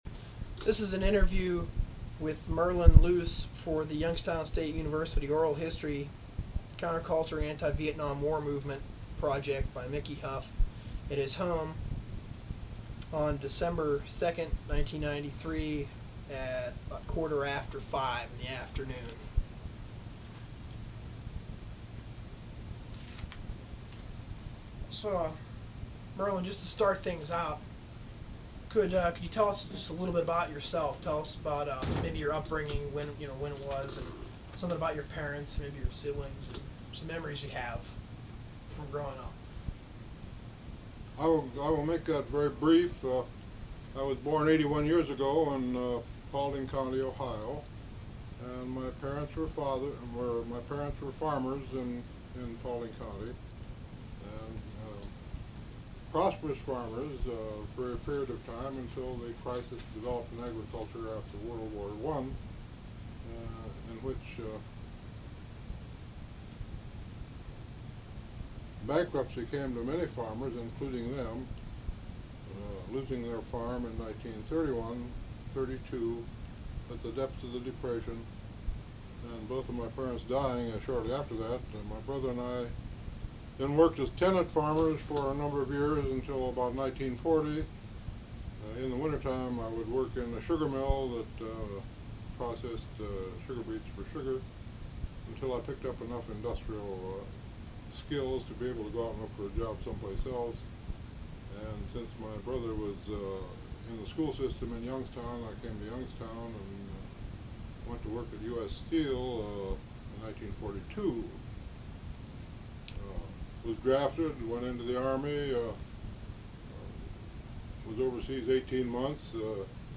Transcript of interview taped on December 2, 1993.